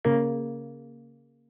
shutdown.wav